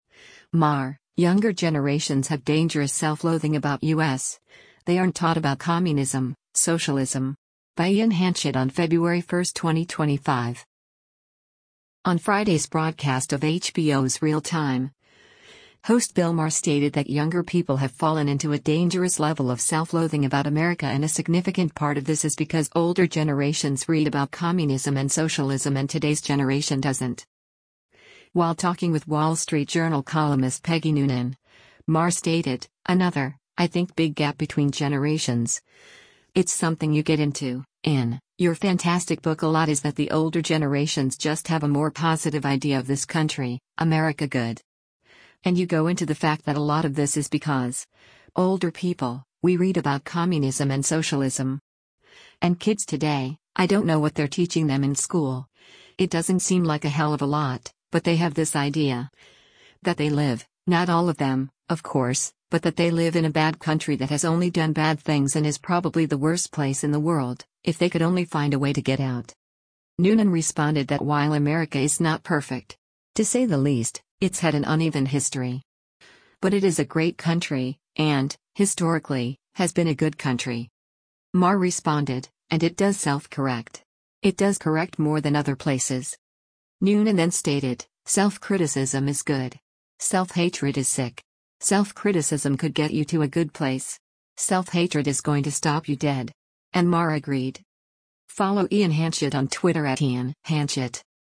On Friday’s broadcast of HBO’s “Real Time,” host Bill Maher stated that younger people have fallen into a dangerous level of self-loathing about America and a significant part of this is because older generations “read about Communism and socialism” and today’s generation doesn’t.